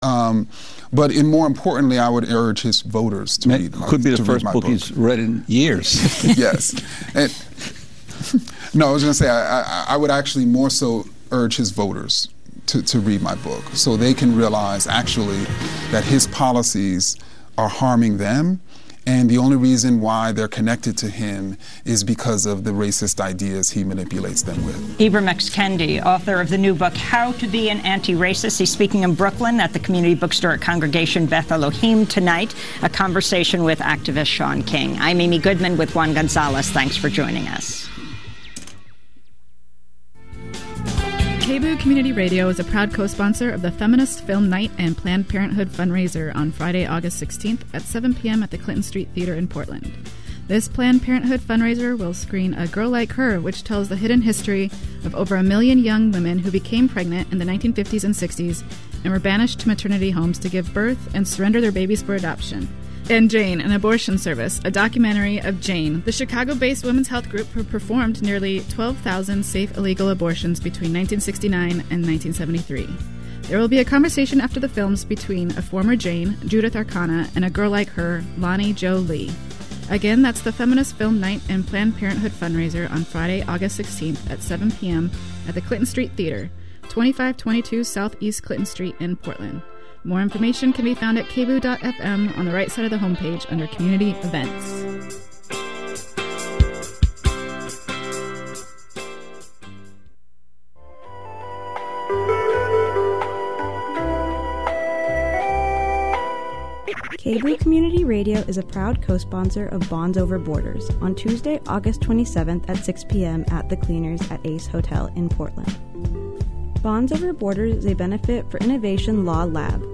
Interview with Rose City ANTIFA